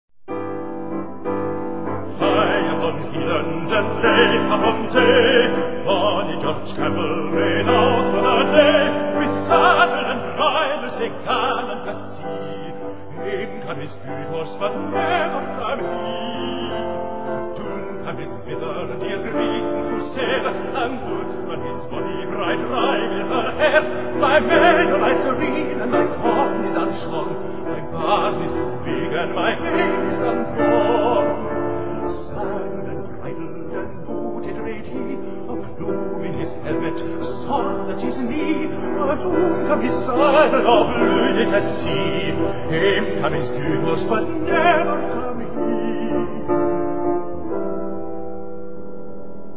Folk_Ballad.mp3